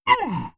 Vampire_Hurt4.wav